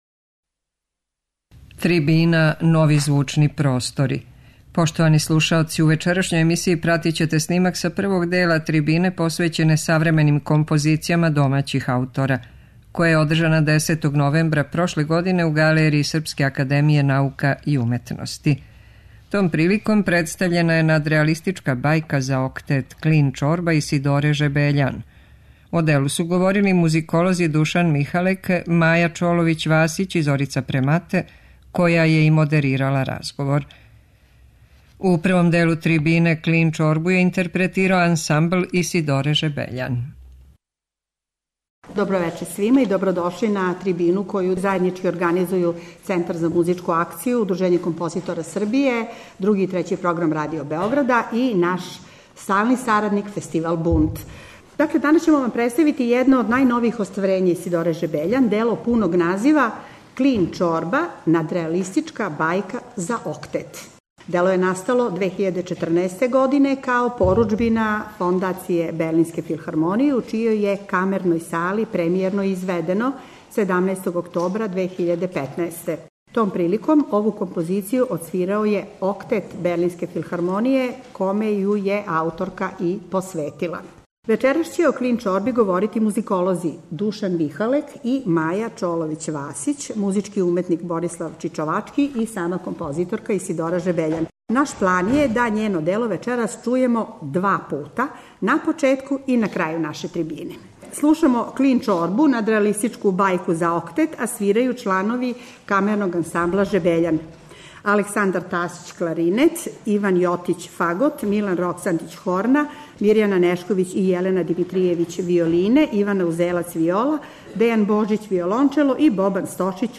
Слушаћете снимак са првог дела трибине посвећене савременим композицијама домаћих аутора, која је одржана 10. новембра прошле године у галерији Српске академије наука и уметности.